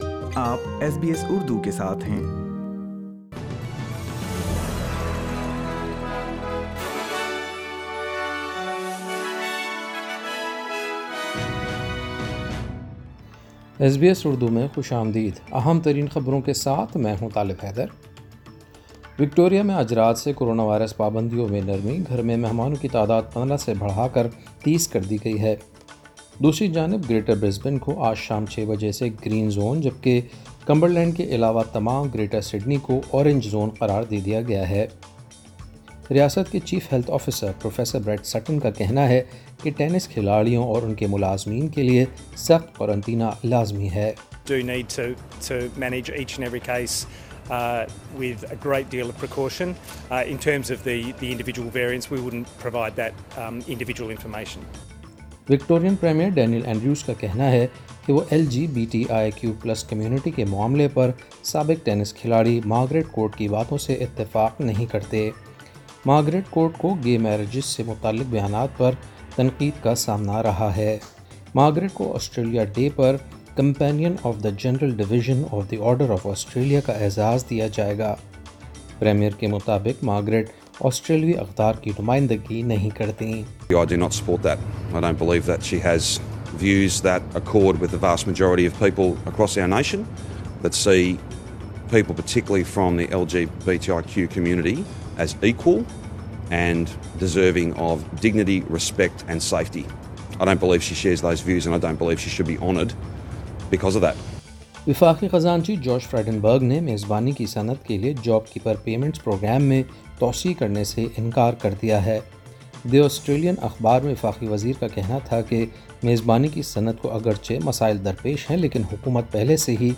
وکٹوریہ میں کچھ کرونا وائرس پابندیوں میں نرمی، آسٹریلوی ٹینس اسٹارایش بارٹی نے سُپرمارکیٹ میں ماسک نہ پہننے پر معافی مانگ لی۔ خبریں سنیئے اردو میں۔